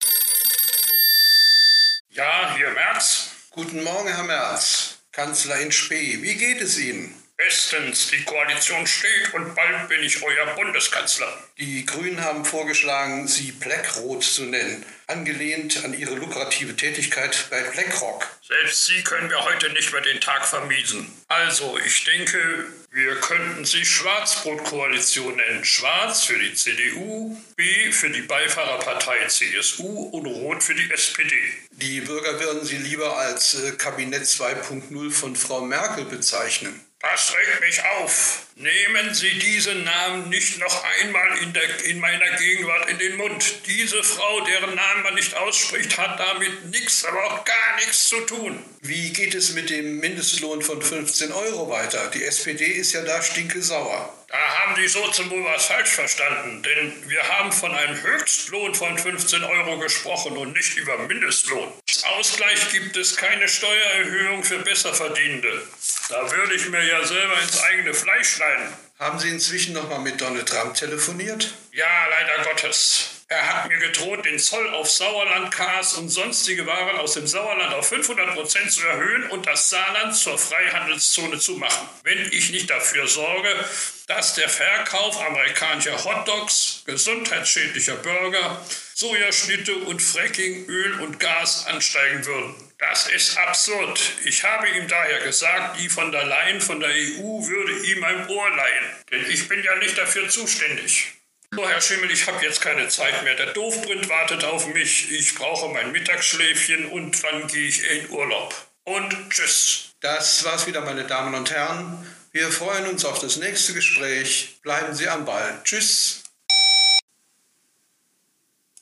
Merz ist gut drauf und sinniert über einen Koalitionsnamen. Er echauffiert sich über Merkel und die neuen sauerländischen Zölle von Donald Trump.